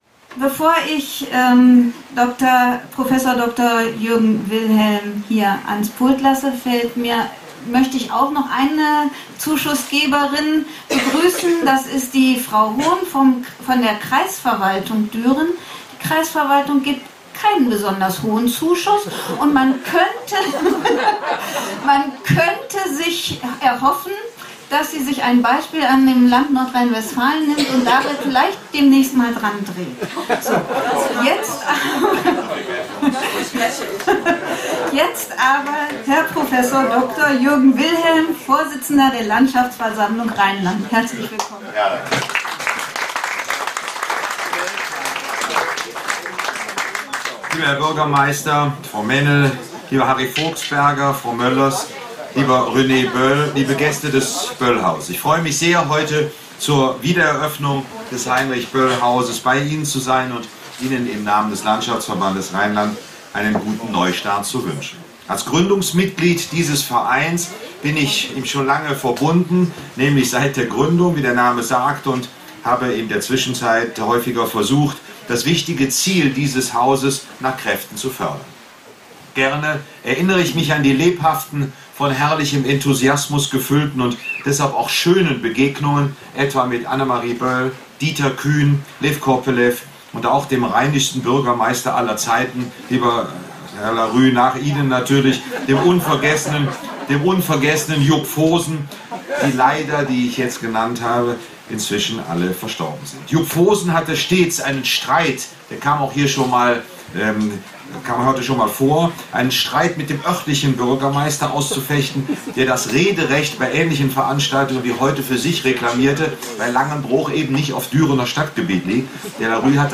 Blick ins Zelt, wo die Ansprachen und Lesungen gehalten wurden.
Sommerfest im Heinrich-Böll-Haus Langenbroich: „100 Jahre Böll“ (Audio 3/7)
Prof. Dr. Jürgen Wilhelm[23] („Landschaftsversammlung Rheinland“[24, 25]) zur Dringlichkeit von Kulturarbeit in Zeiten von neuen Diktaturen[26] (Audio 3/7) [MP3]